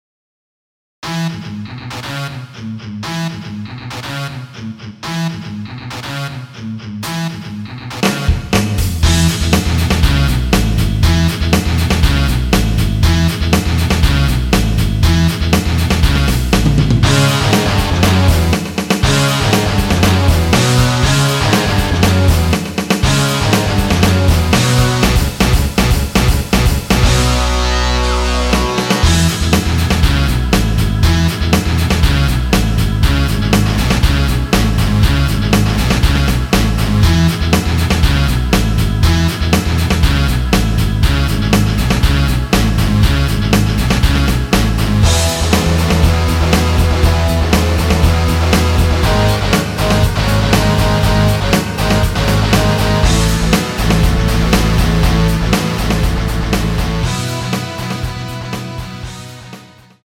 앞부분30초, 뒷부분30초씩 편집해서 올려 드리고 있습니다.
중간에 음이 끈어지고 다시 나오는 이유는